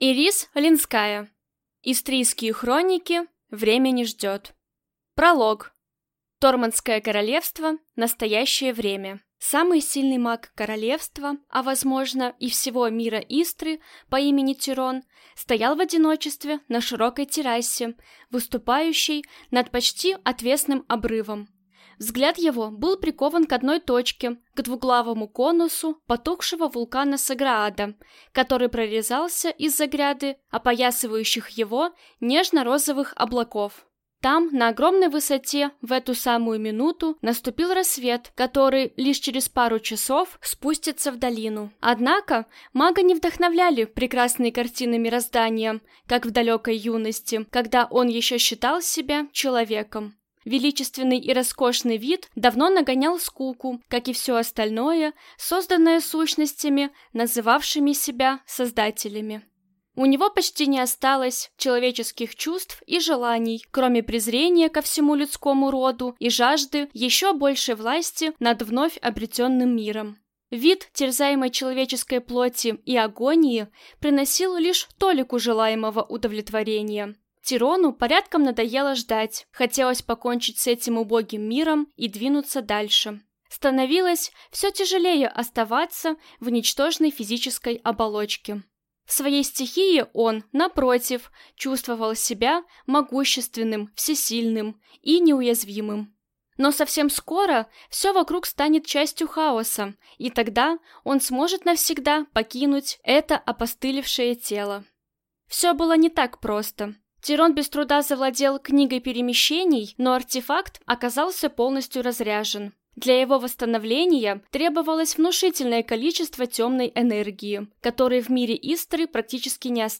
Аудиокнига Истрийские Хроники. Время не ждет | Библиотека аудиокниг